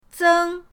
zeng1.mp3